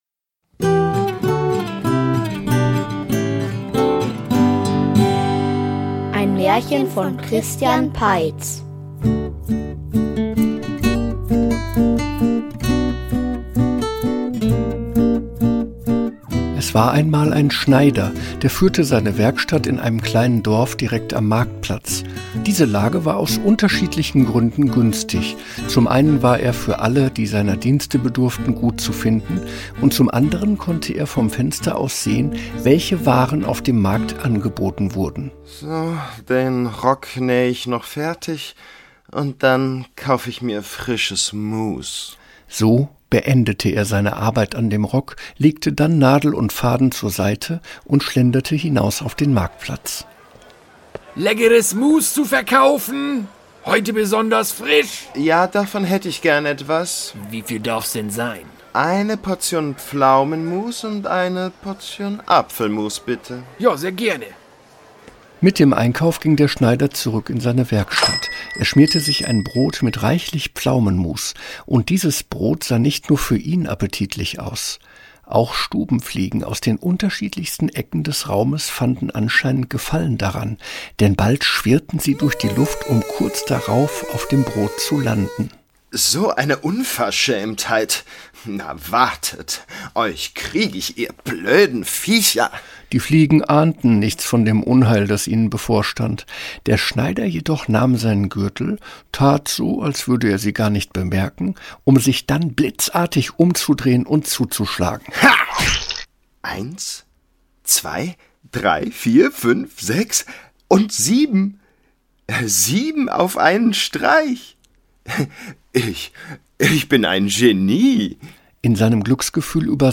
Die achte Fliege --- Märchenhörspiel #67 ~ Märchen-Hörspiele Podcast